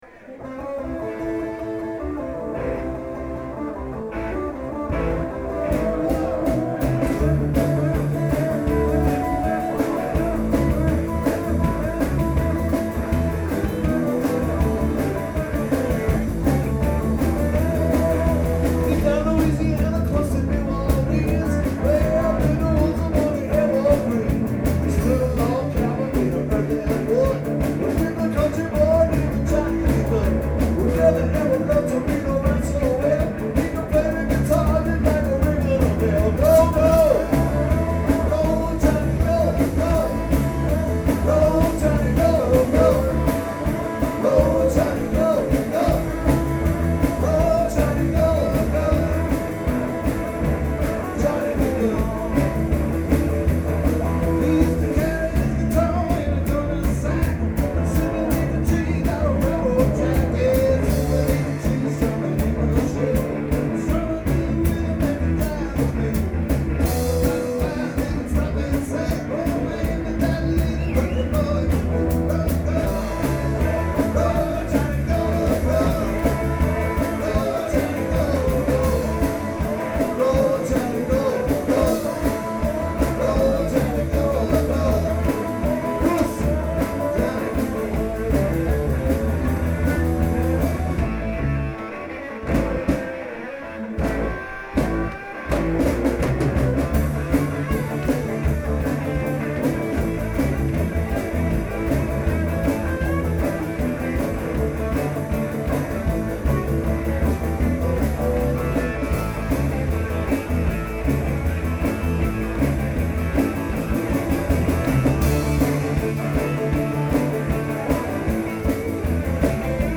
SPECIAL JAM